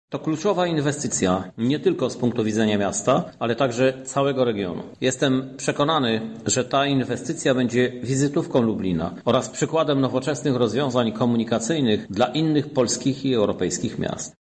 Po wielu latach starań Lublin będzie miał dworzec na miarę wyzwań XXI w. – mówi Prezydent Miasta Lublin Krzysztof Żuk: